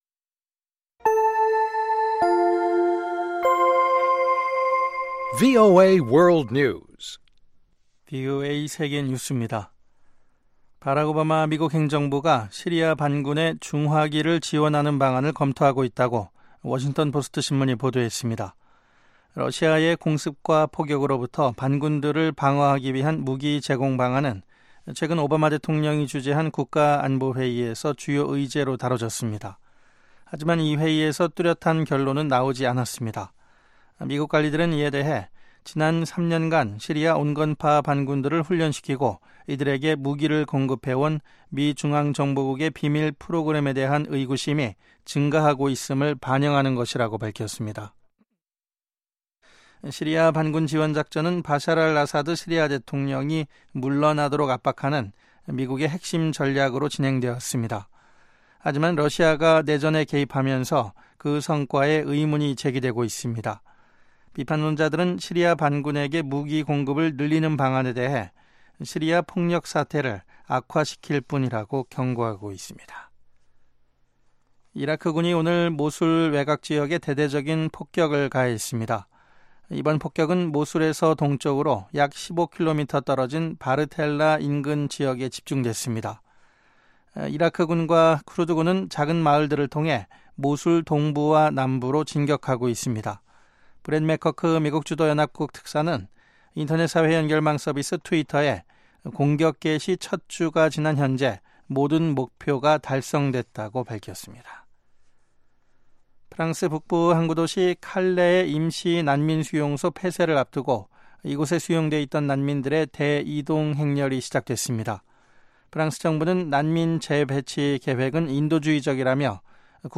VOA 한국어 방송의 간판 뉴스 프로그램 '뉴스 투데이' 3부입니다. 한반도 시간 매일 오후 11:00 부터 자정 까지, 평양시 오후 10:30 부터 11:30 까지 방송됩니다.